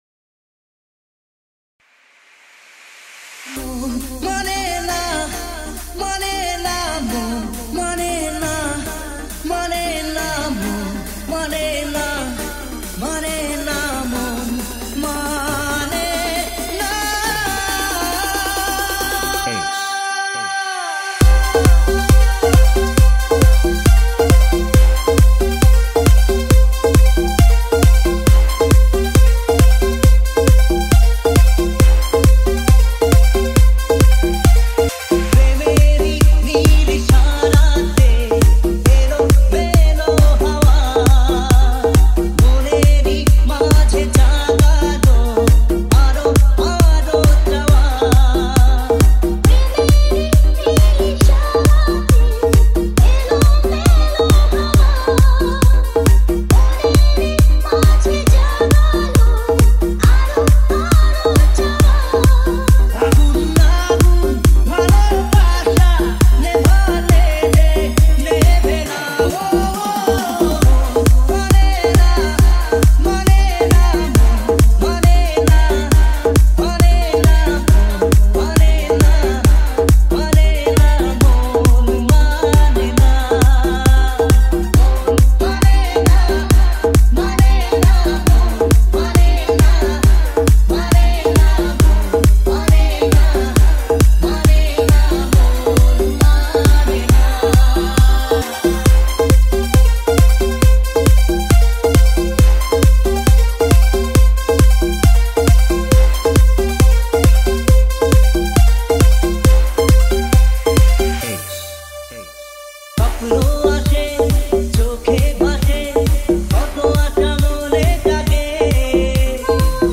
Edm Humming Bass Mix